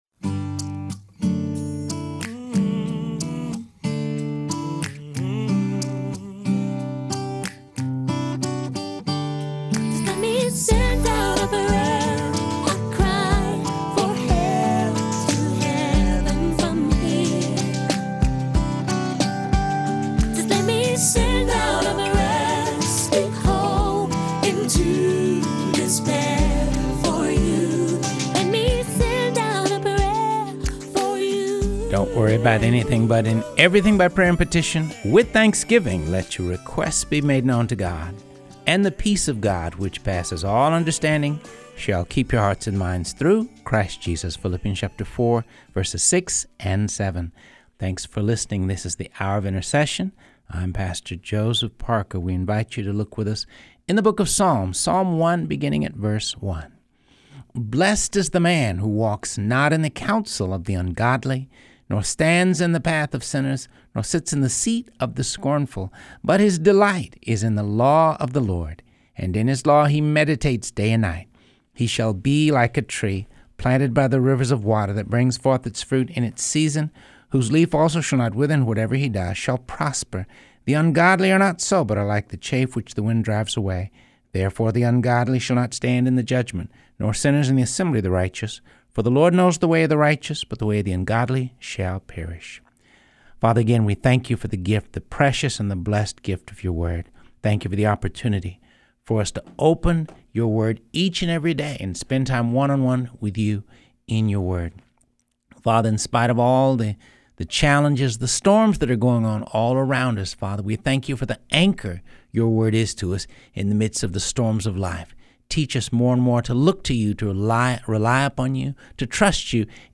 reading the Bible